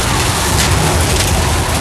tyres_gravel_skid.wav